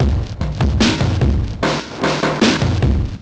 Hip Hop Saved Me Loop.wav